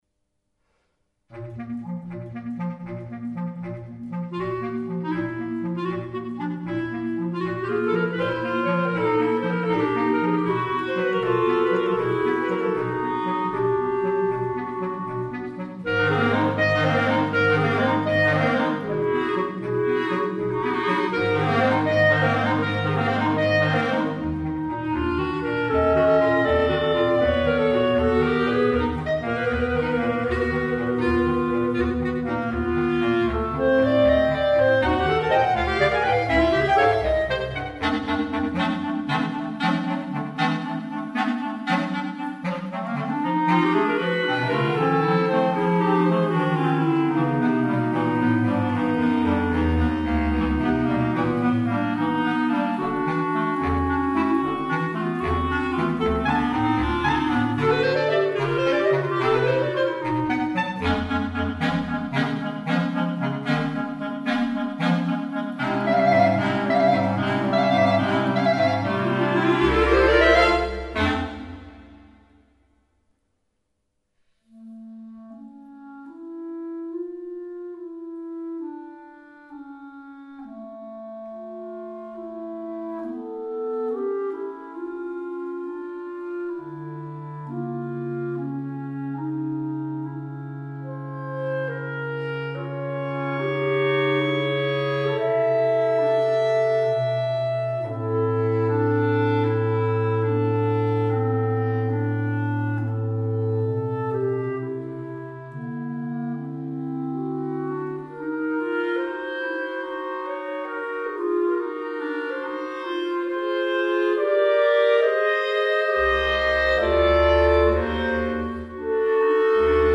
Composizione Musica da Camera.
quartetto di clarinetti